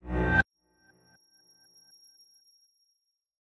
meleeattack-impacts-magicaleffects-psychic-00.ogg